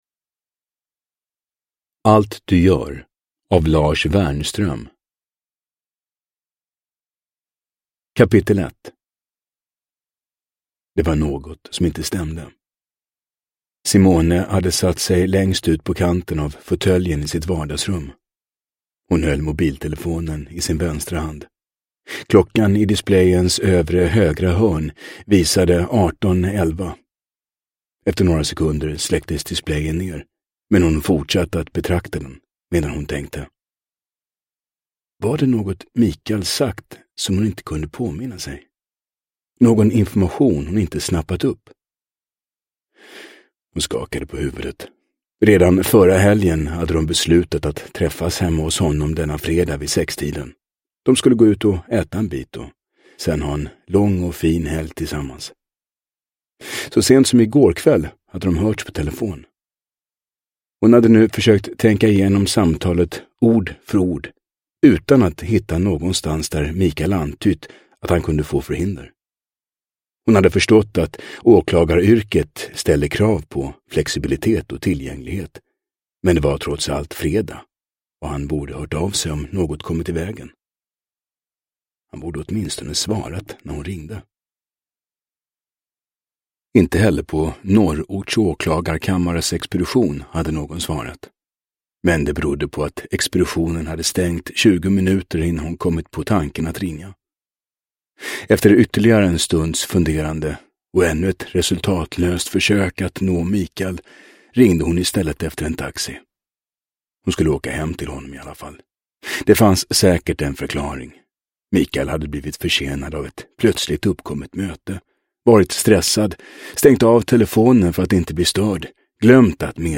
Allt du gör – Ljudbok – Laddas ner